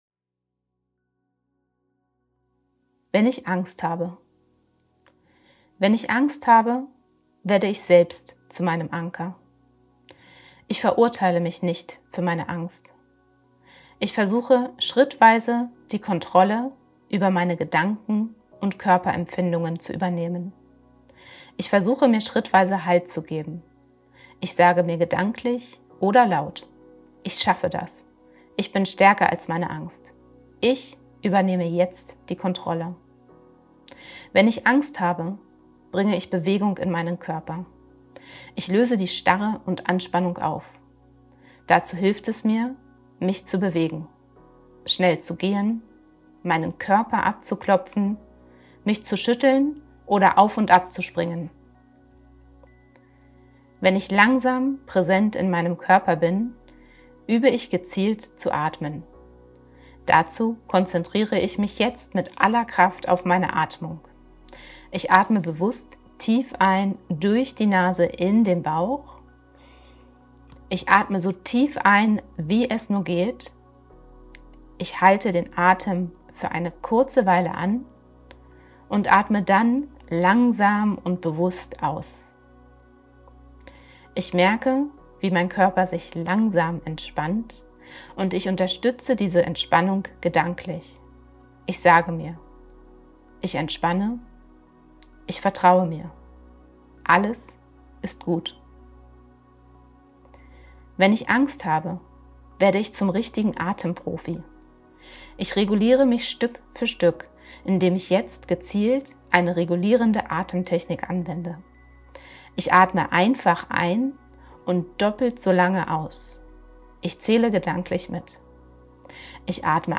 Wenn-ich-Angst-habe_mit-Musik.mp3